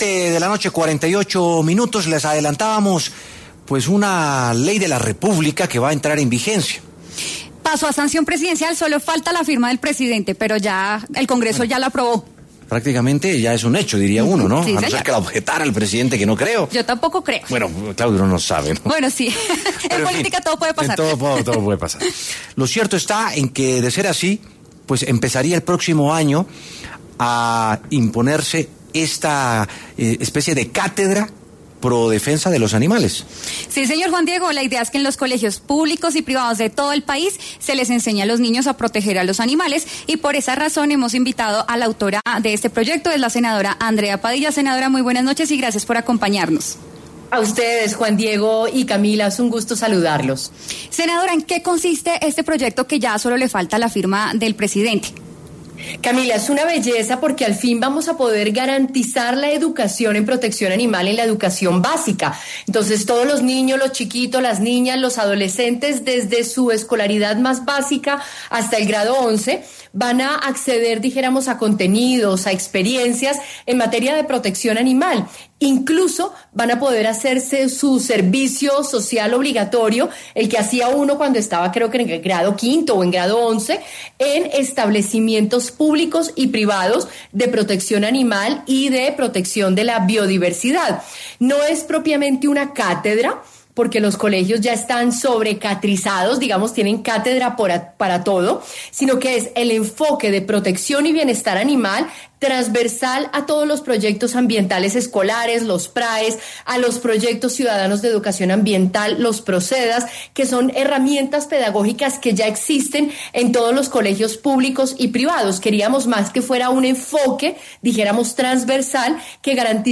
La senadora Andrea Padilla, autora de la iniciativa llamada Ley Empatía, habló en W Sin Carreta para explicar el proyecto al que solo le falta la sanción presidencial para ser ley de la República.